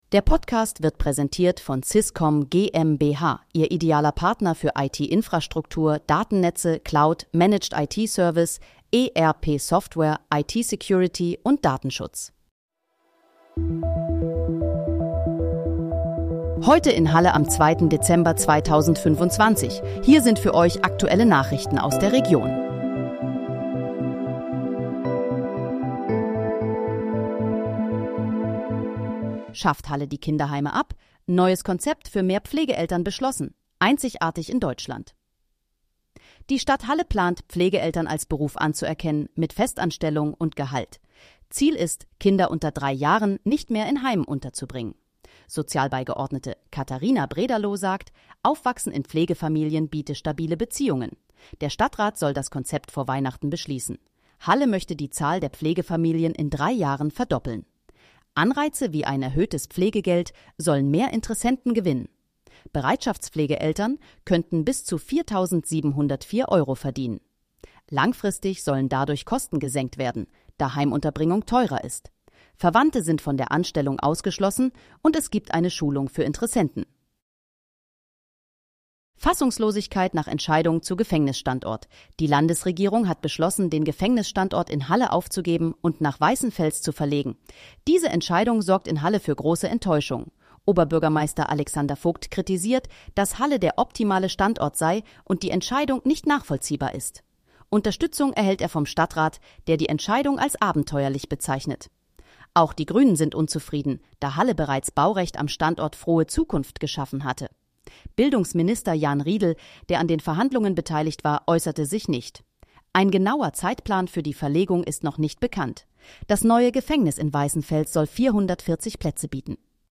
Heute in, Halle: Aktuelle Nachrichten vom 02.12.2025, erstellt mit KI-Unterstützung
Nachrichten